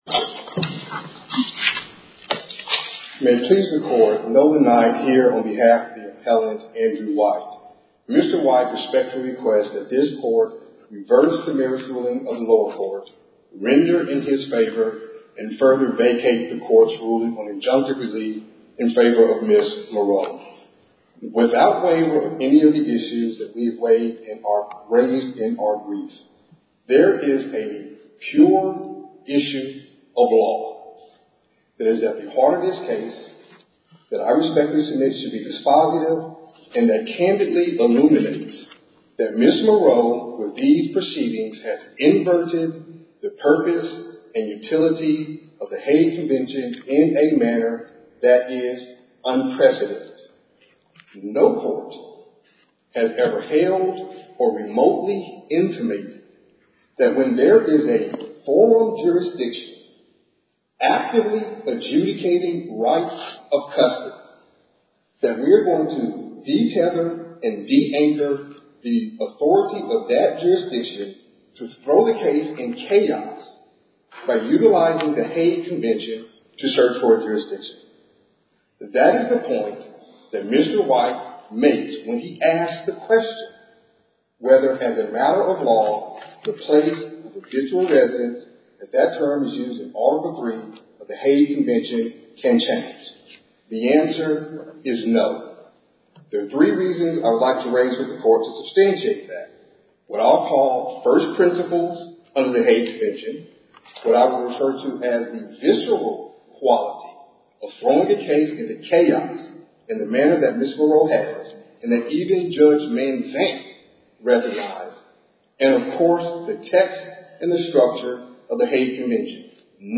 Oral Argument